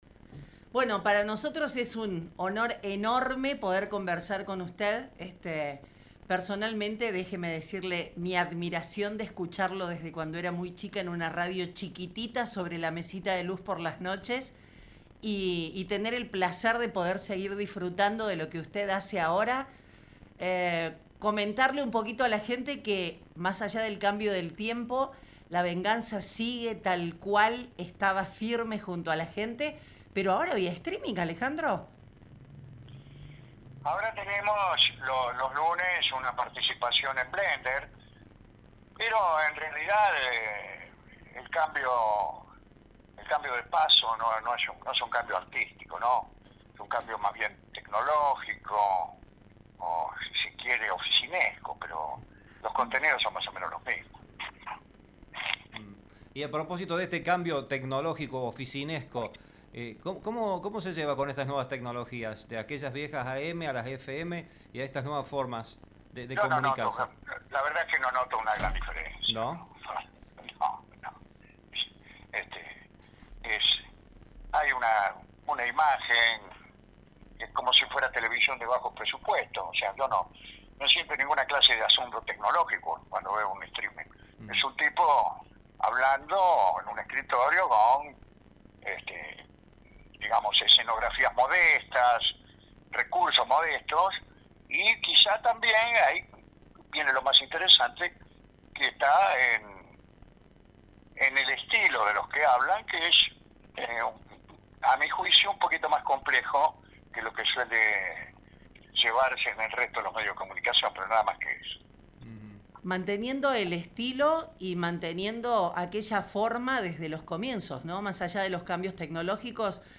En una charla profunda y cercana, conversamos con Alejandro Dolina sobre su vida, su recorrido en la radio, la creación de su universo literario y musical, y una serie de temas que atraviesan su mirada única del mundo.